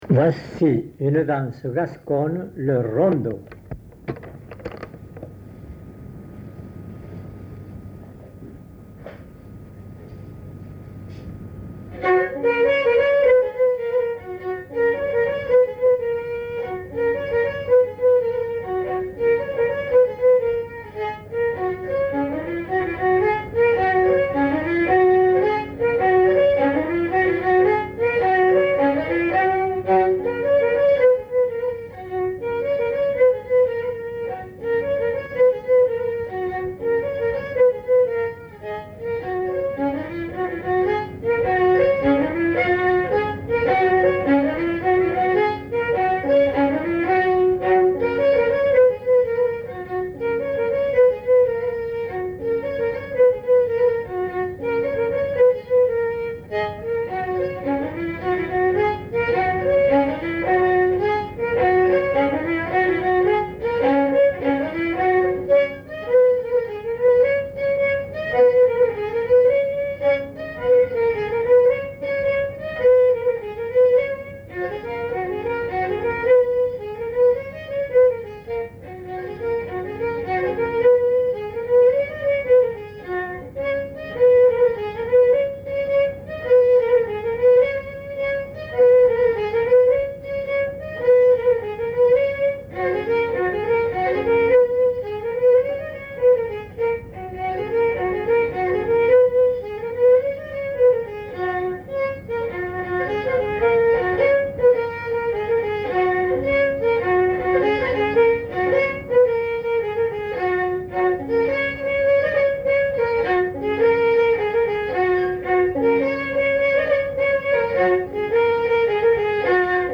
Lieu : Auterive
Genre : morceau instrumental
Instrument de musique : violon
Danse : rondeau